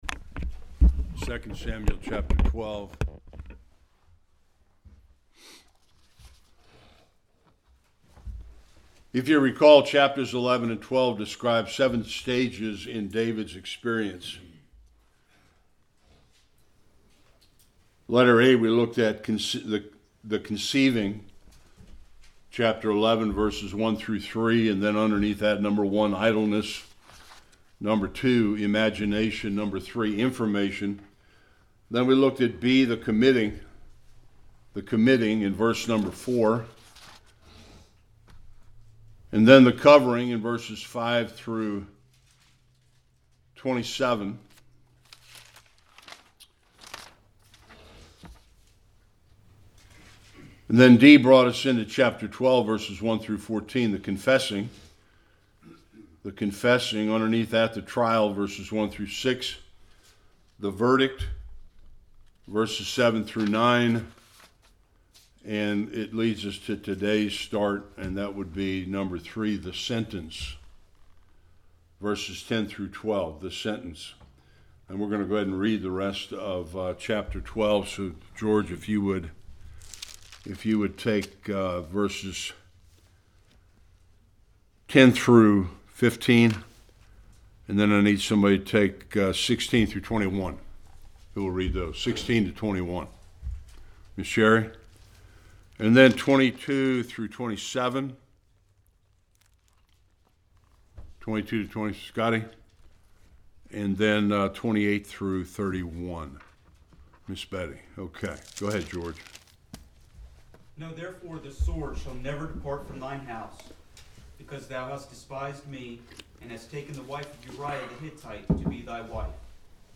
1-14 Service Type: Sunday School King David’s hardships continued due to his sins of adultry and murder.